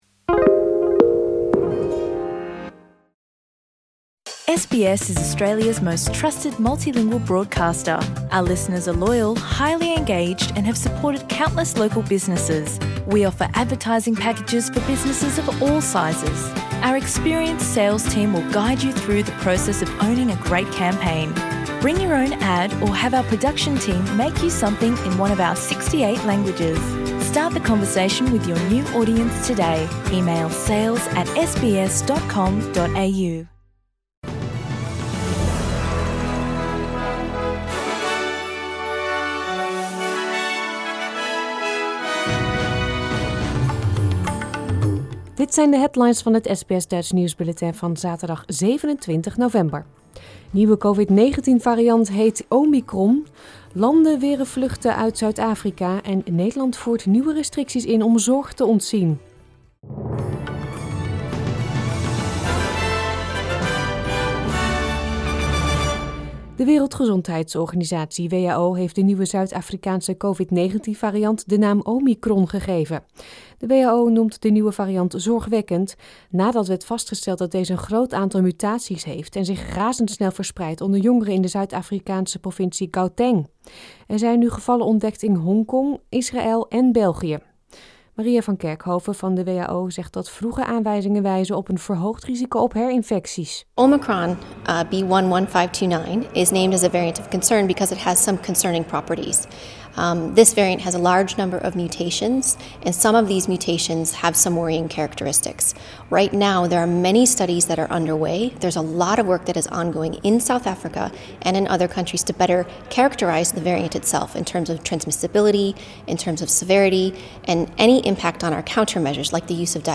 Nederlands / Australisch SBS Dutch nieuwsbulletin van zaterdag 27 november 2021